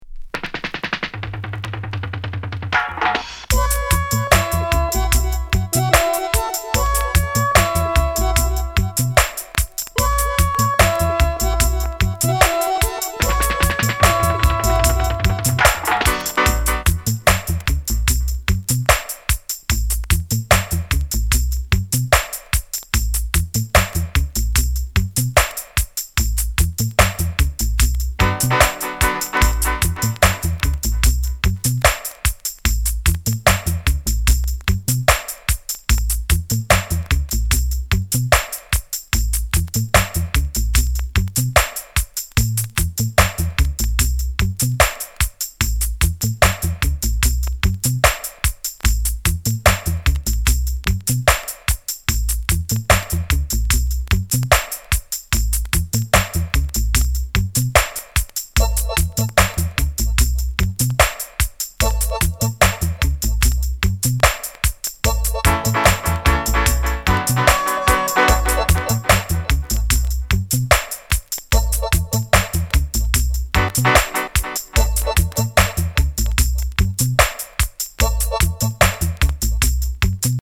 Genre: Reggae / Dancehall